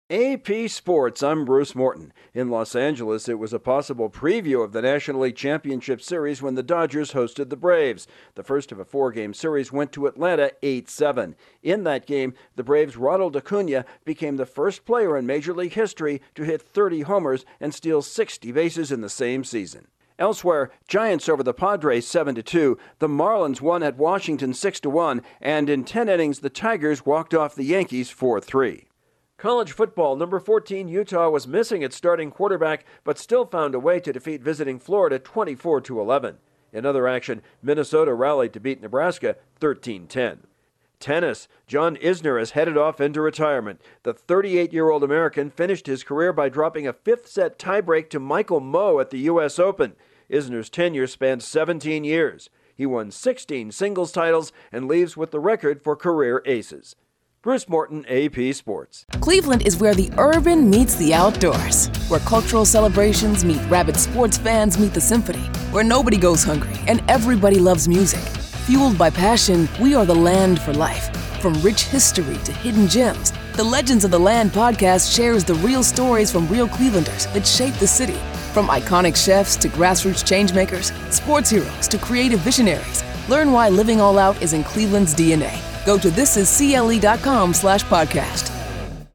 The Braves strike first in a matchup of baseball's top team, the Giants and Marlins improve their playoff hopes, Utah downs Florida and an American tennis star ends his career. Correspondent